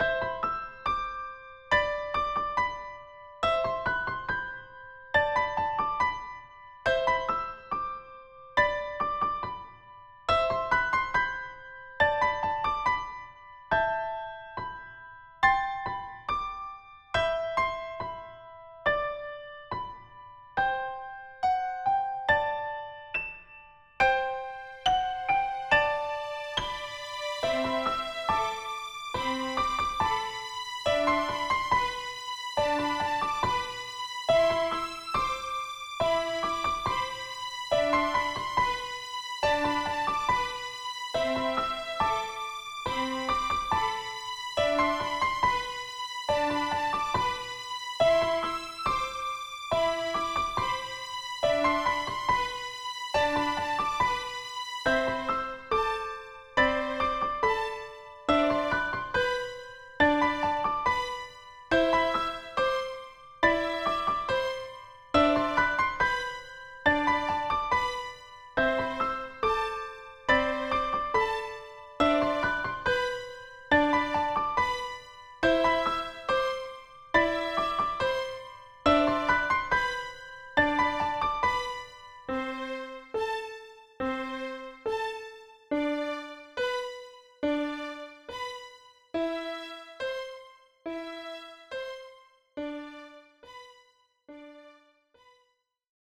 Yet another Christmas/Snow type of music!
strings high pitch piano Christmas snow snowy